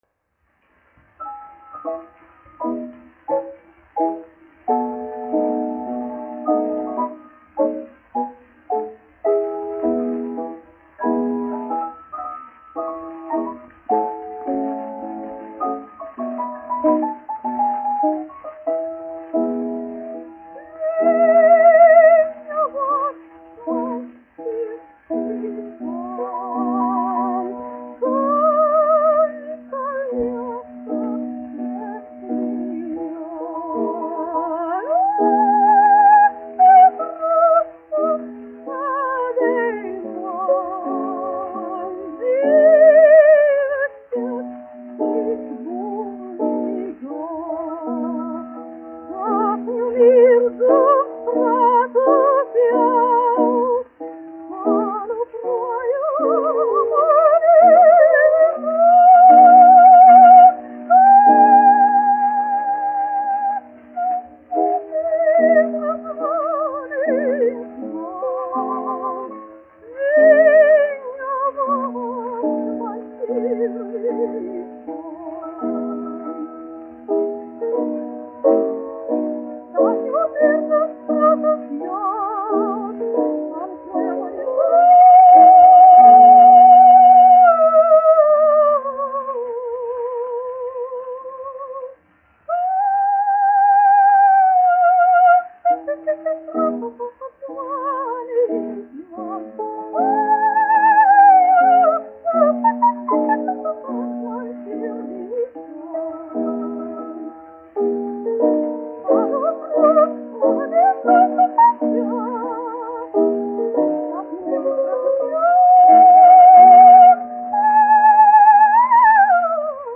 1 skpl. : analogs, 78 apgr/min, mono ; 25 cm
Operas--Fragmenti, aranžēti